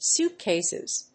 /ˈsuˌtkesɪz(米国英語), ˈsu:ˌtkeɪsɪz(英国英語)/